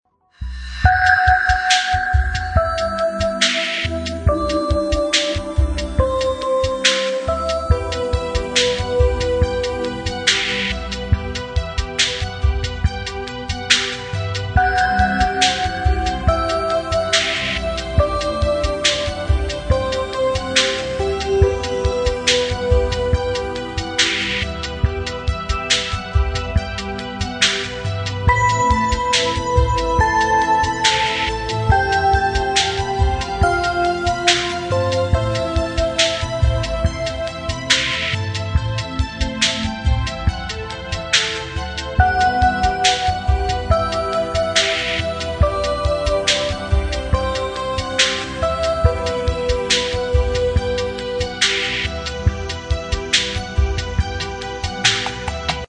Saxophone,
Sitar,